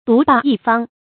獨霸一方 注音： ㄉㄨˊ ㄅㄚˋ ㄧ ㄈㄤ 讀音讀法： 意思解釋： 霸占一個地方（多指壞人）。